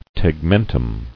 [teg·men·tum]